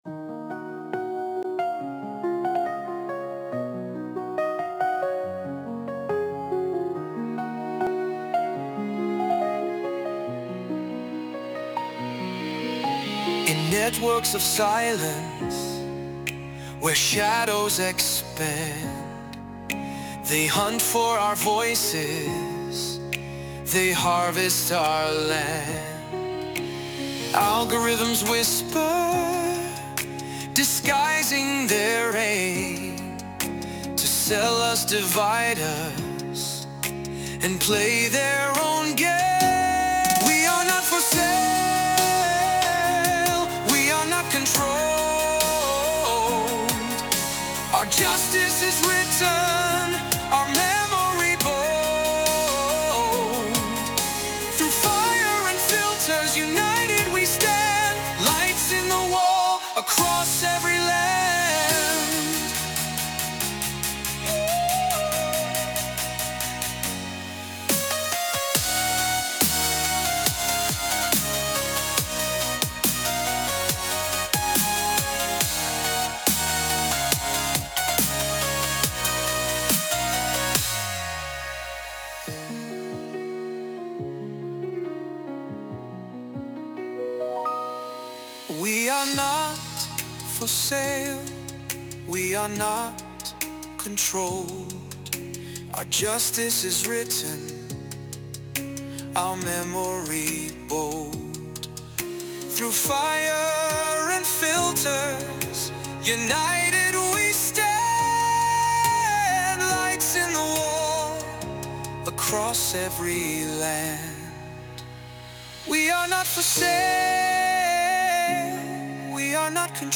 Download der MP3-Dateien: Version1 3:04: Lichter in der Mauer – Der DSGVO-Song gegen digitale Ausbeutung | KI-komponierte Europa-Hymne
Die musikalische Darbietung ermöglichte Suno AI, eine auf Musik spezialisierte KI-Plattform.
im Stil von „progressivem Rock“ generiert – inklusive Gesang, Gitarren, Synths und Chor.